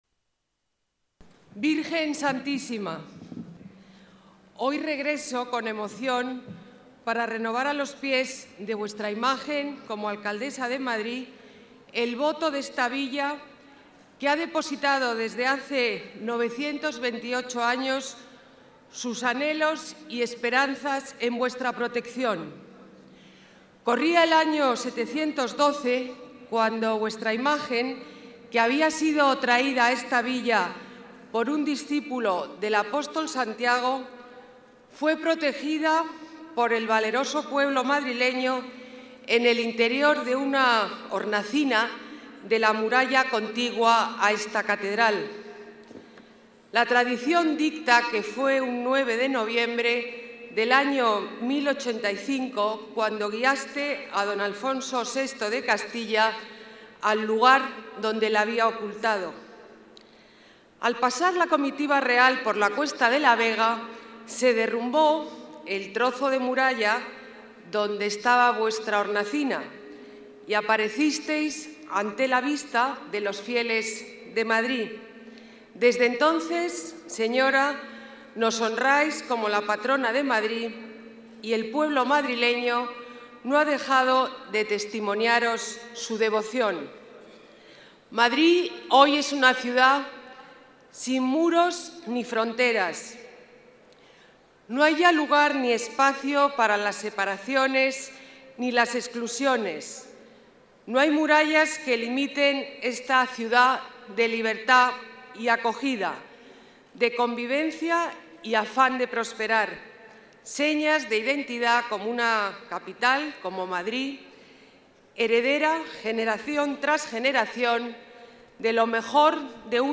Madrid celebra hoy la festividad de su patrona, la Virgen de La Almudena. Ana Botella, alcaldesa de la ciudad, ha renovado el tradicional Voto de la Villa en la Catedral madrileña.